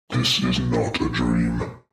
جلوه های صوتی
دانلود صدای ربات 9 از ساعد نیوز با لینک مستقیم و کیفیت بالا